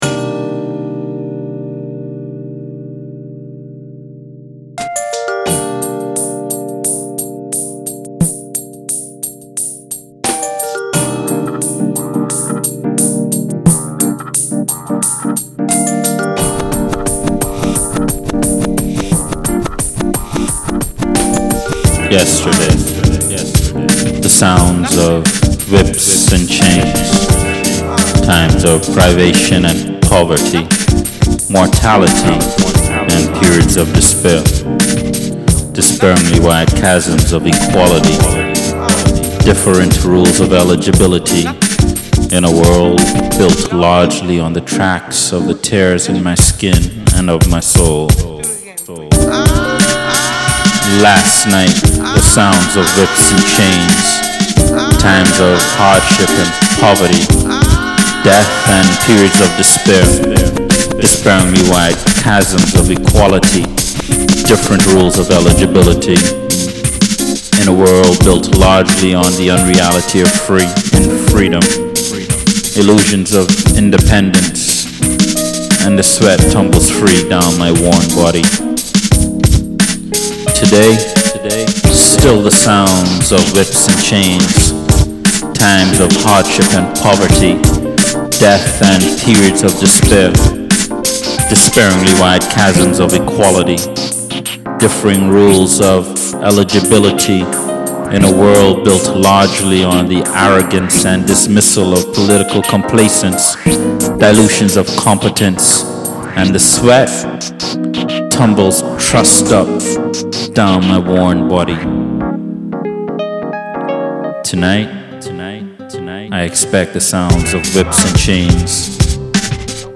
is a member of Ruff Kutz, a performance collective whose goal is to bring audible life to lettered work.